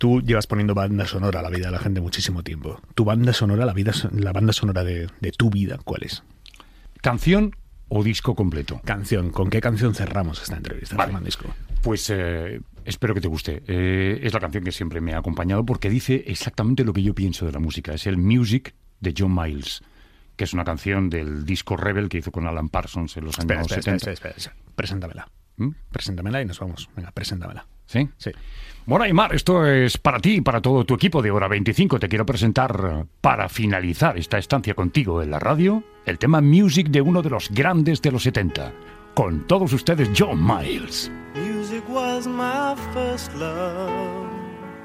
Fragment final d'una entrevista a Fernando Martínez "Fernandisco".
Info-entreteniment